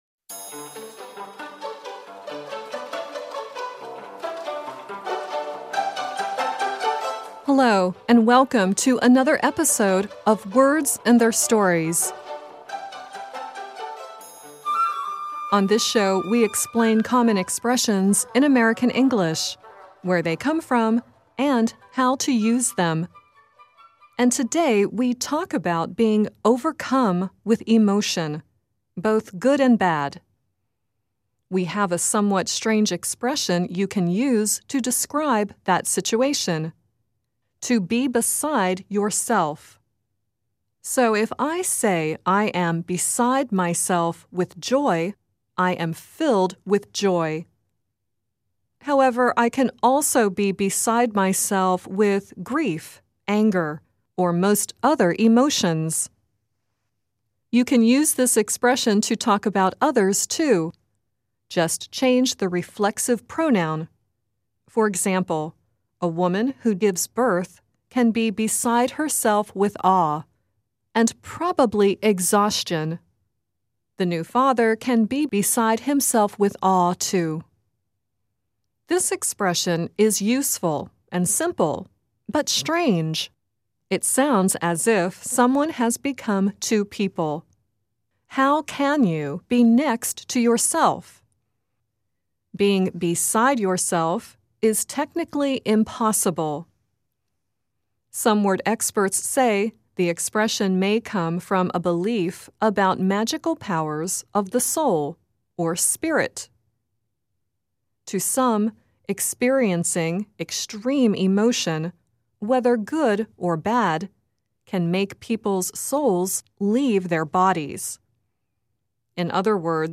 The song at the end is Ricky Skaggs singing "I'm Beside Myself.